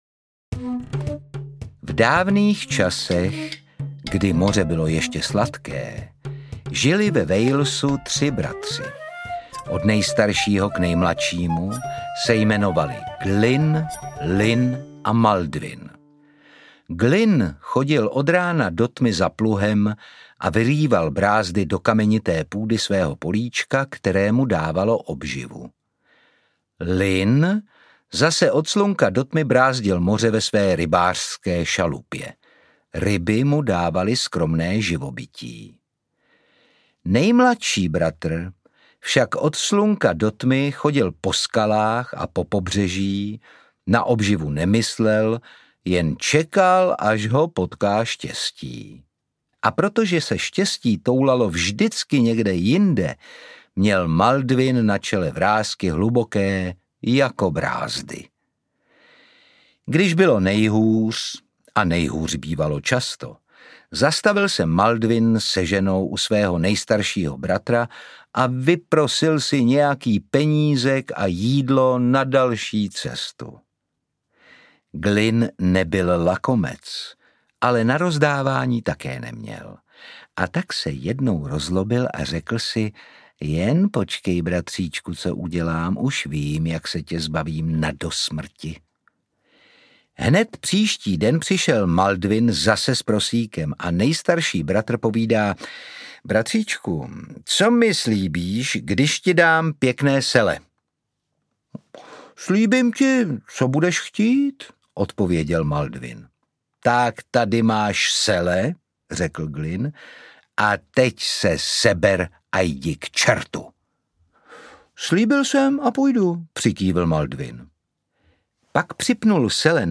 Kočičí král audiokniha
Ukázka z knihy
Čte Otakar Brousek.
Vyrobilo studio Soundguru.
• InterpretOtakar Brousek ml.